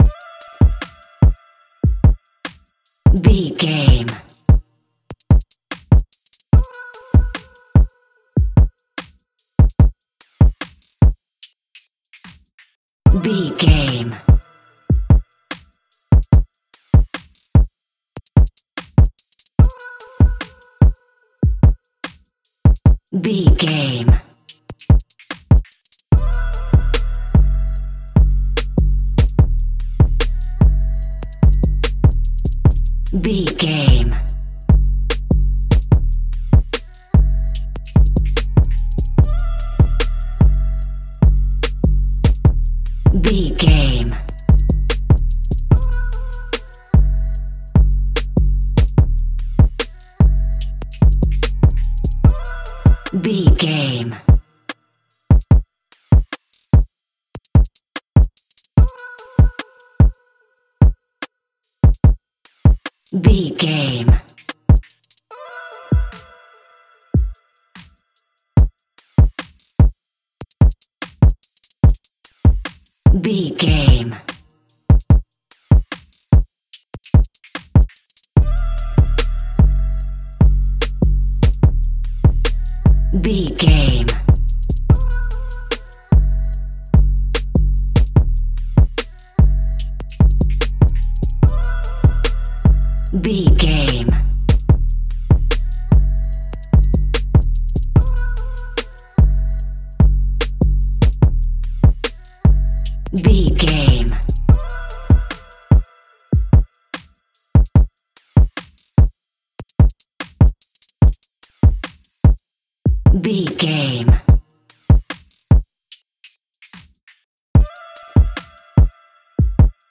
Aeolian/Minor
aggressive
intense
driving
bouncy
dramatic
energetic
dark
drum machine
bass guitar
synthesiser